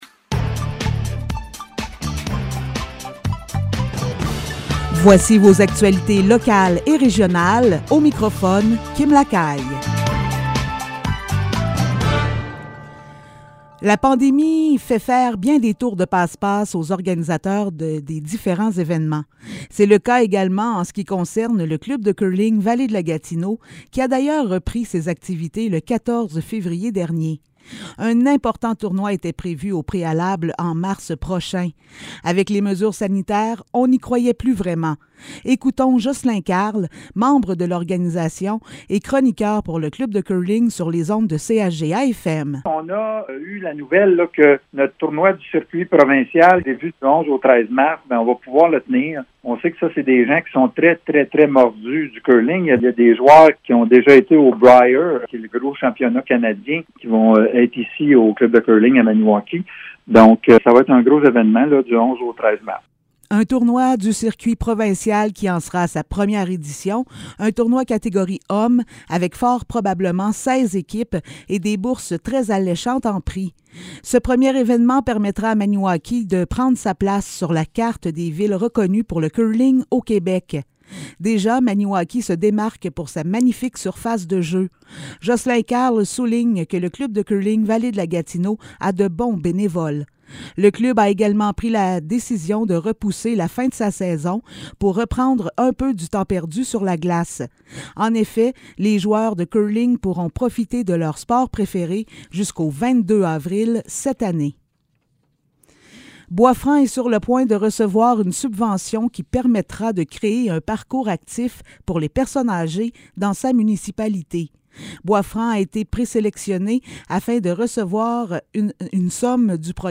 Nouvelles locales - 17 février 2022 - 15 h